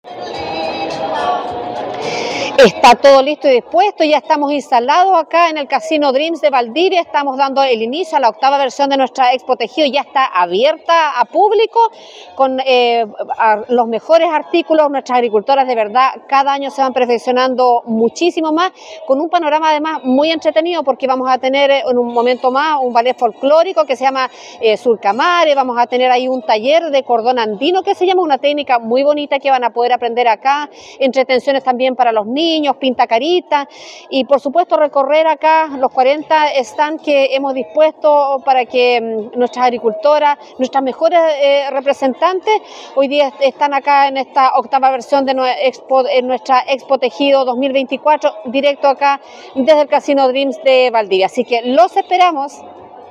La directora regional de INDAP Los Ríos, Alejandra Bartsch, reafirmó el llamado a ser parte de este evento, “a través de estas 42 expositoras y expositores queremos representar a cada una de las mujeres y hombres que con mucho esfuerzo trabajan la lana en nuestra región, rubro que queremos fortalecer y poner en valor.
cuna-directora-expo-tejidos-viernes-19.mp3